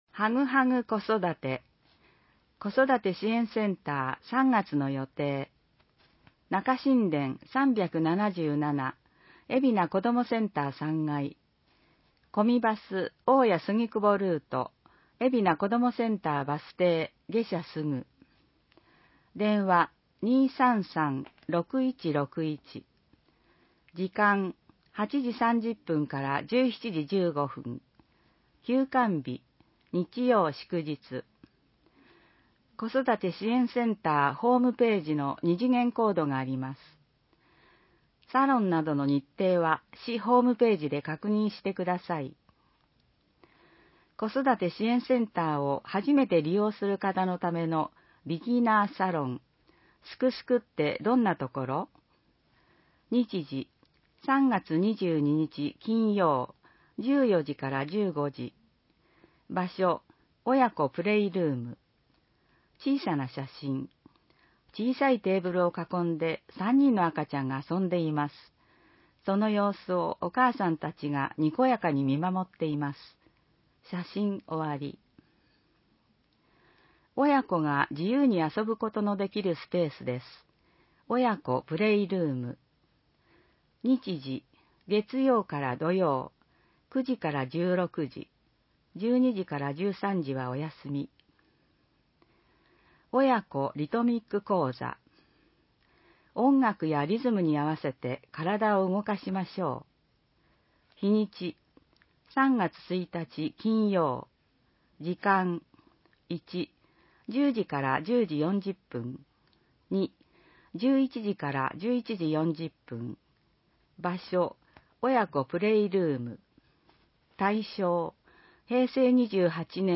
広報えびな 平成31年2月15日号（電子ブック） （外部リンク） PDF・音声版 ※音声版は、音声訳ボランティア「矢ぐるまの会」の協力により、同会が視覚障がい者の方のために作成したものを登載しています。